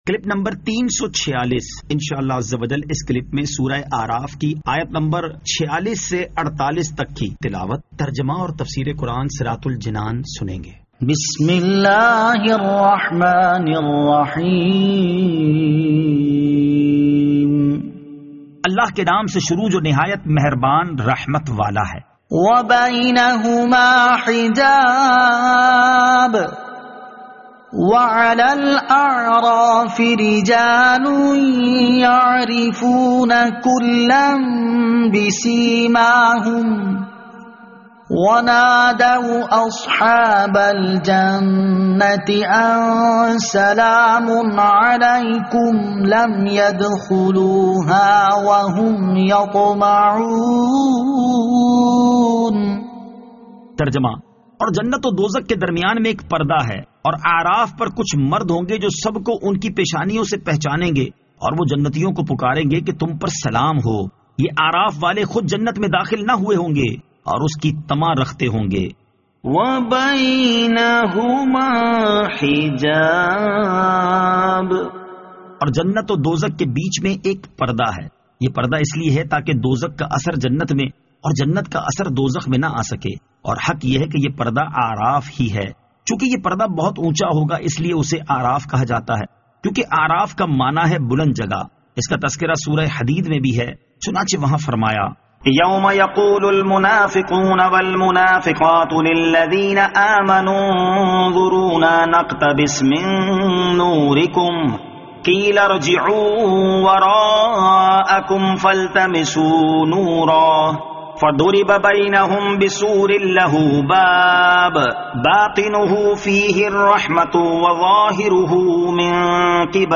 Surah Al-A'raf Ayat 46 To 48 Tilawat , Tarjama , Tafseer